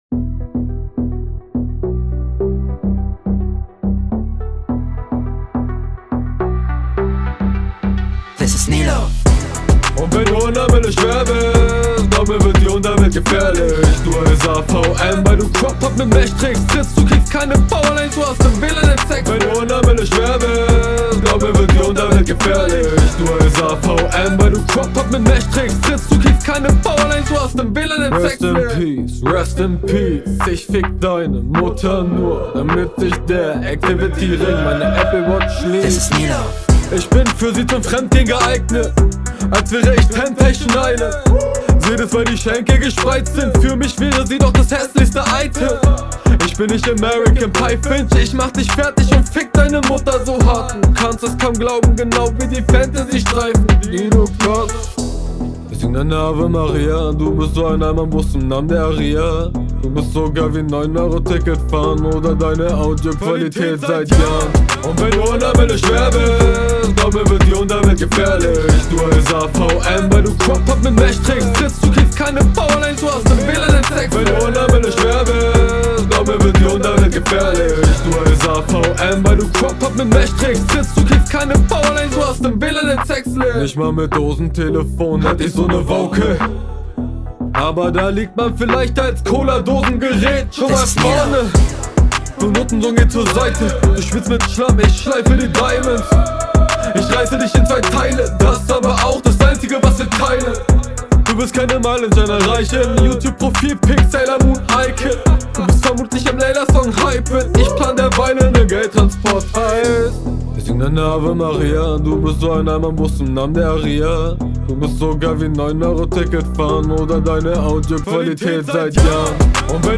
Die Mische ist ein bisschen kurz gekommen, hab ich das Gefühl.